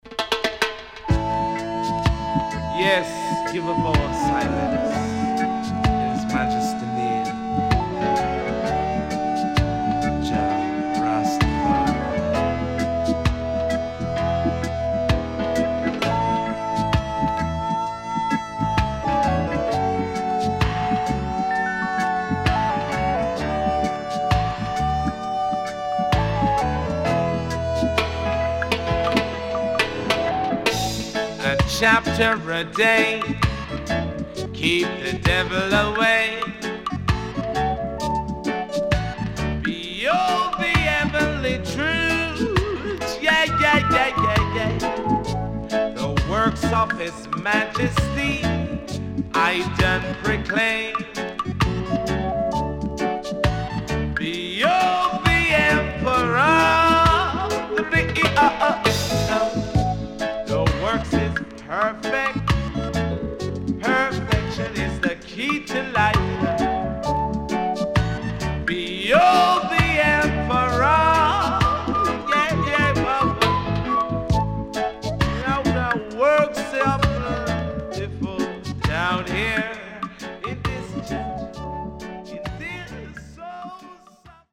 SIDE B:プレス起因で少しチリノイズ入ります。